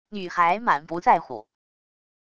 女孩满不在乎wav音频